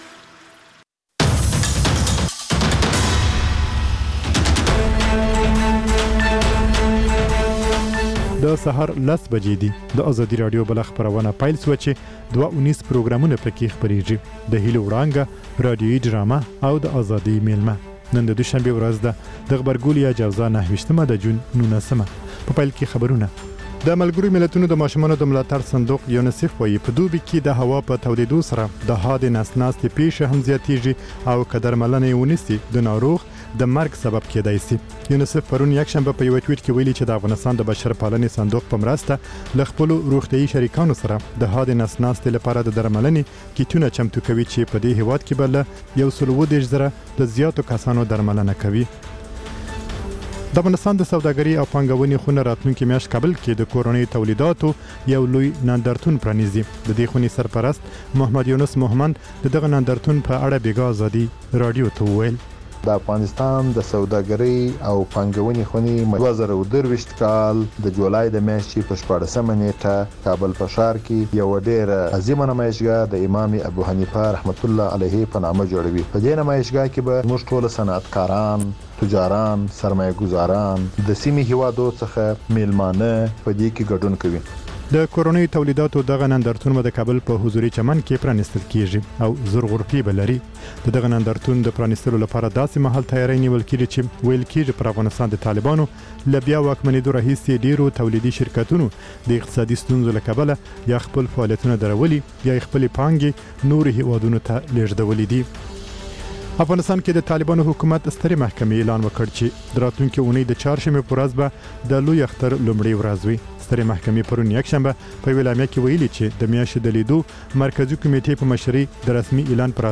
لنډ خبرونه